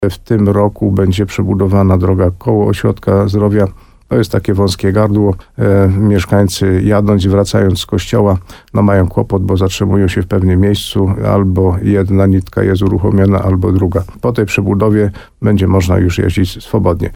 Jak mówi wójt gminy Ropa Karol Górski, potrzeb jest bardzo dużo.